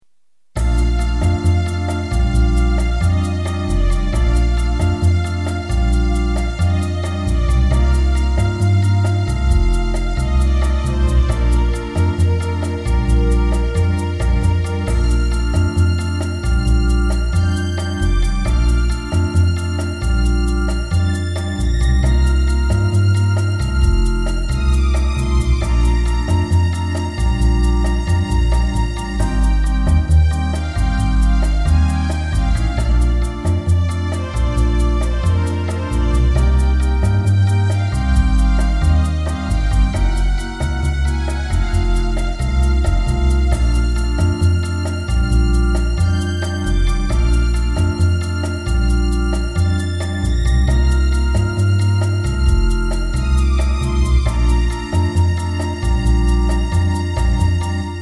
(recorded midi)